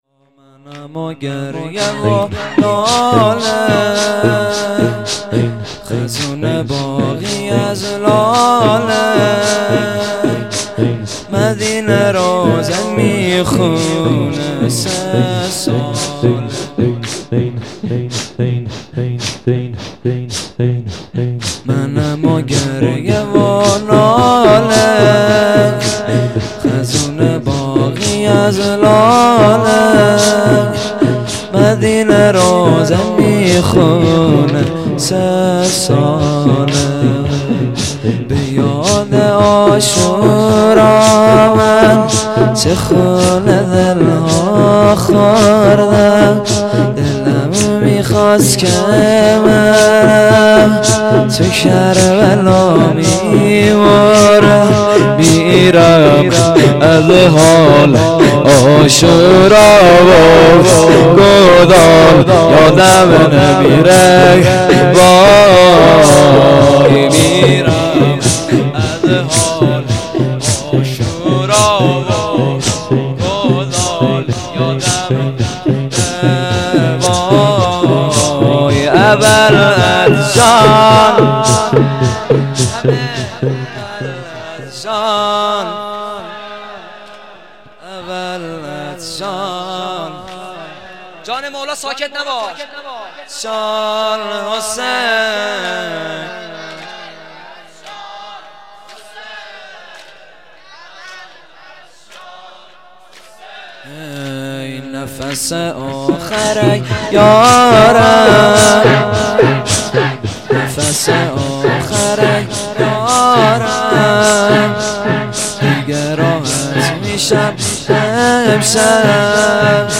زمینه | منمُ گریهُ ناله
جلسه هفتگی 96/09/29